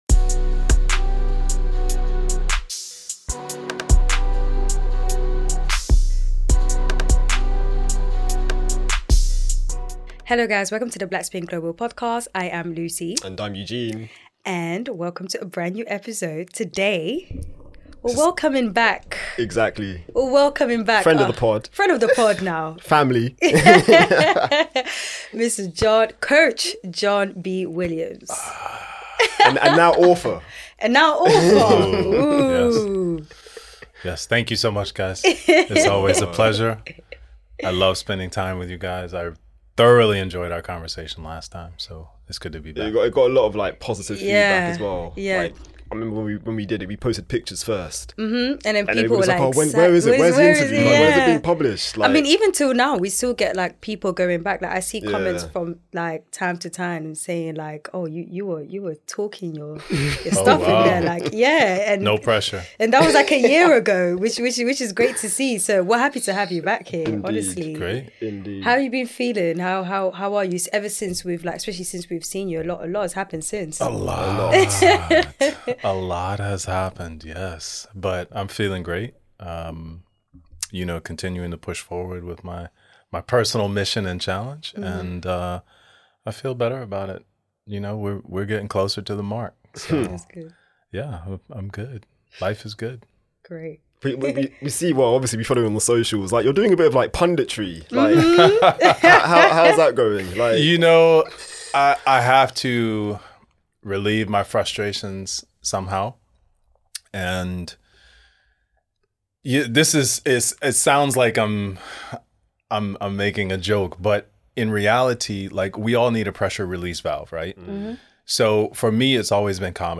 *Disclaimer: this interview was recorded during the 2nd week of Wimbledon 2024.